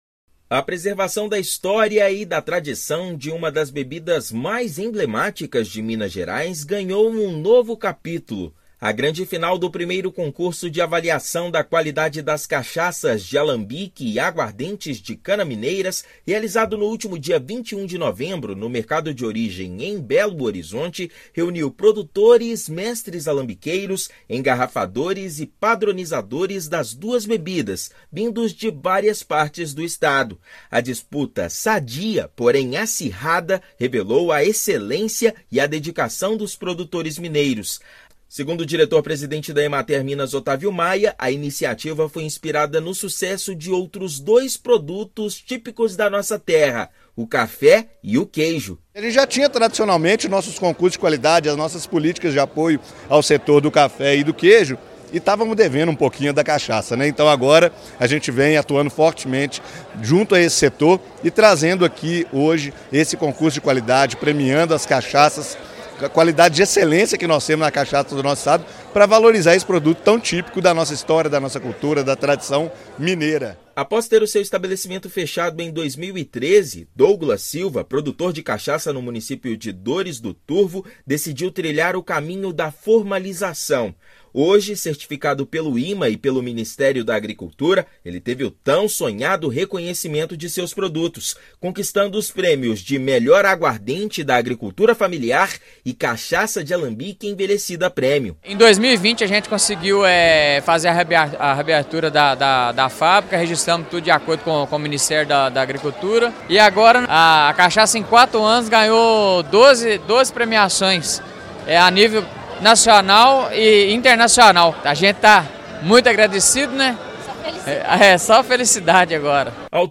Na primeira edição do concurso, foram premiadas 15 cachaças e 4 de aguardentes. Ouça matéria de rádio.